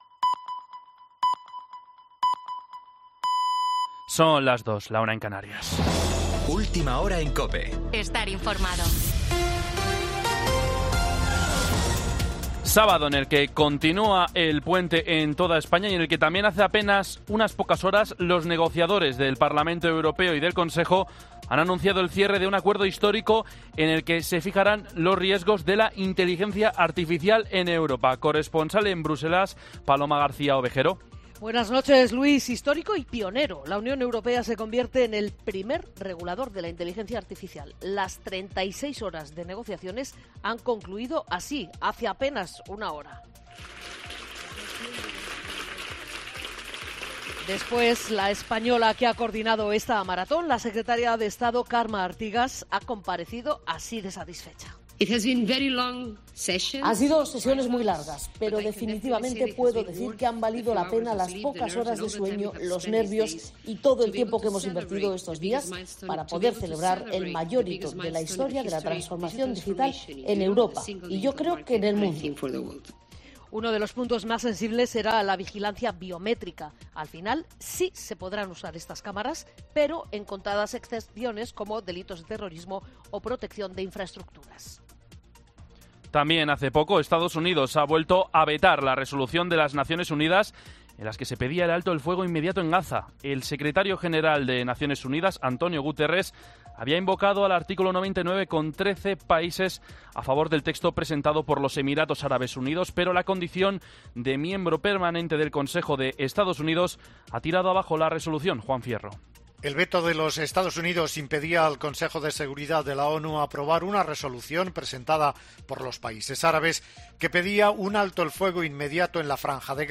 Boletín